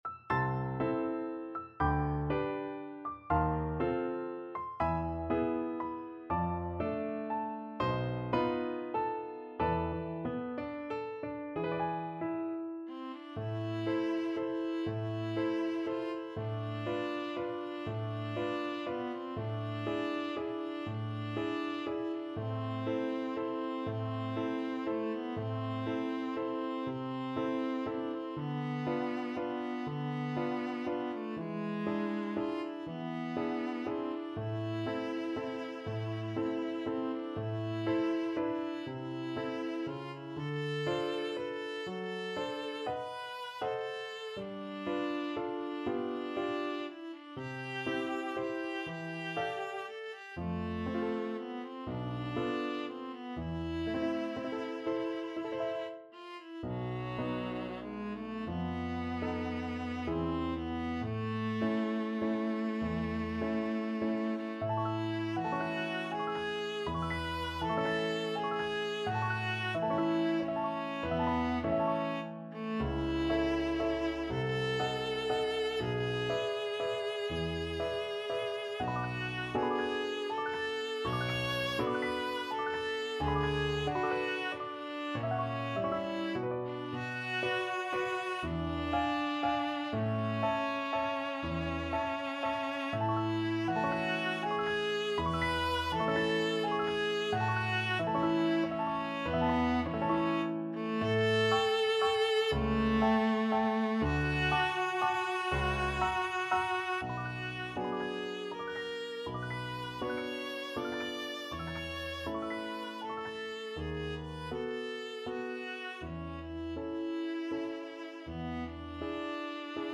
~ = 120 Lento
3/4 (View more 3/4 Music)
Classical (View more Classical Viola Music)